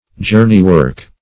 Search Result for " journeywork" : The Collaborative International Dictionary of English v.0.48: Journeywork \Jour"ney*work`\, n. 1.
journeywork.mp3